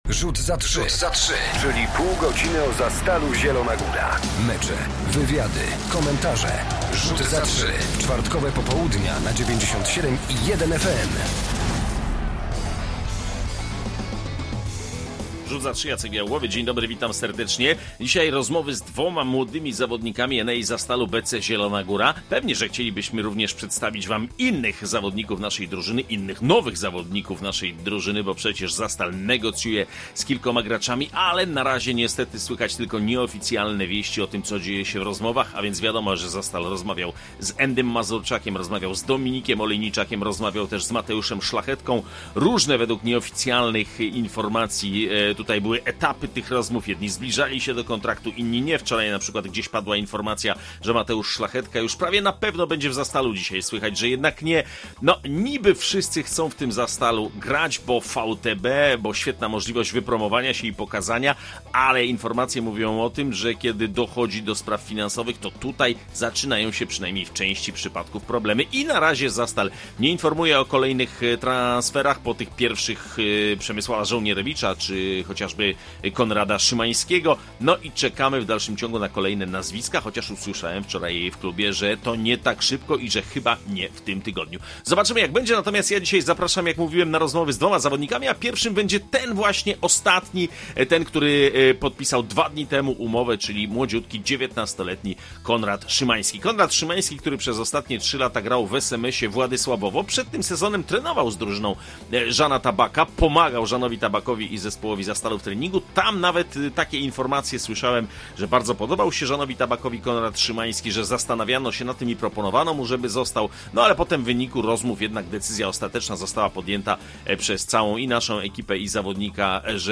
W Rzucie za trzy dziś dwie rozmowy z dwójką młodych graczy - jeden z nich właśnie podpisał kontrakt z klubem